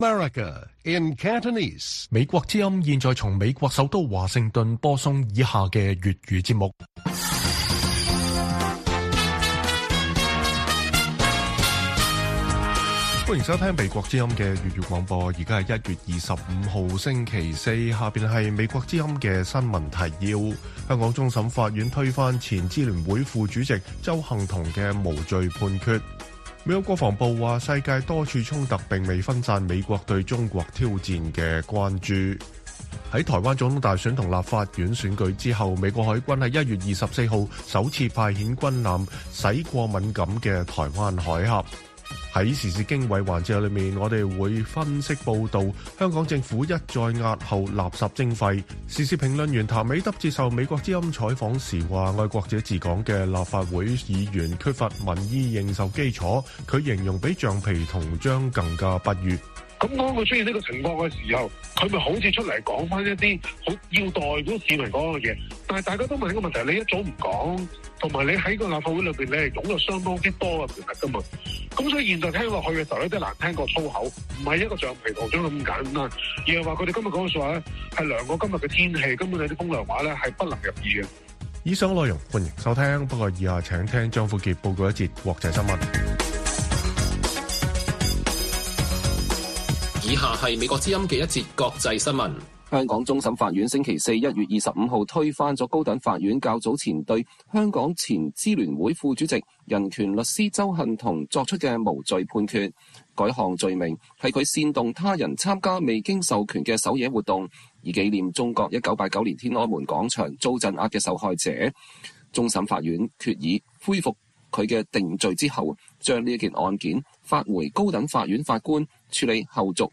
粵語新聞 晚上9-10點: 評論指香港政府押後實施垃圾徵費反映愛國者治港立法粗疏